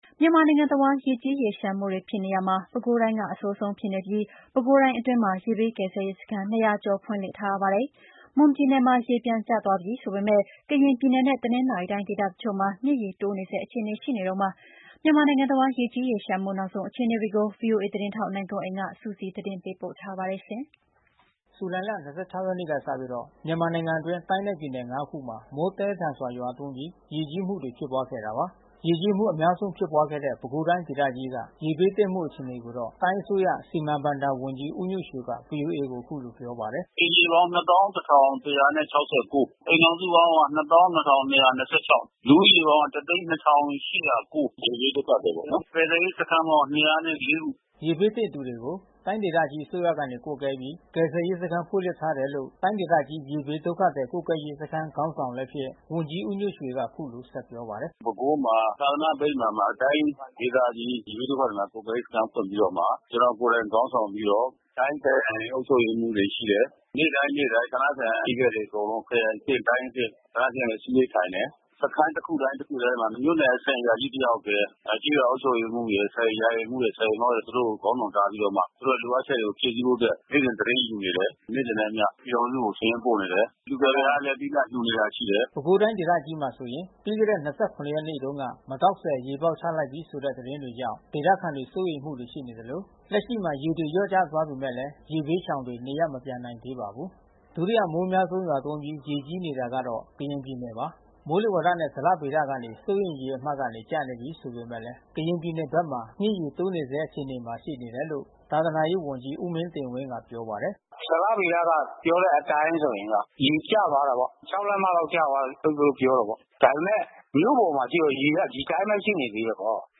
ရေကြီးမှုအများဆုံးဖြစ်ပွါးခဲ့တဲ့ ပဲခူးတိုင်းဒေသကြီးက ရေဘေးသင့်မှုအခြေအနေကိုတော့ တိုင်းအစိုးရ စီမံဘဏ္ဍာ ဝန်ကြီးဦးညွန့်ရွှေက ဗွီအိုအေကို အခုလိုပြောပါတယ်။
မိုးလေဝါသနဲ့ဇလဗေဒကနေ စိုးရိမ်ရေမှတ်ကနေ ကျနေပြီးဆိုပေမယ့် ကရင်ပြည်နယ်ဘက်မှာ မြစ်ရေတိုးနေဆဲ အခြေအနေမှာရှိနေတယ်လို့ သာသနာရေးဝန်ကြီး ဦးမင်းတင်ဝင်းကပြောပါတယ်။